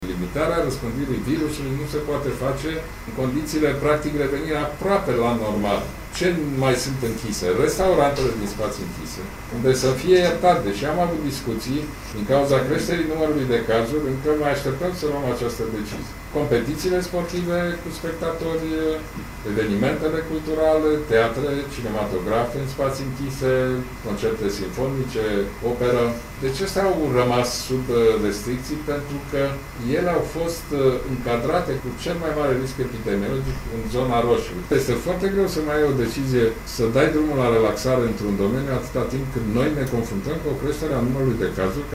Îngrijorat de creșterea numărului de infectari cu noul coronavirus, premierul Ludovic Orban spune că nu se iau în calcul noi măsuri de relaxare.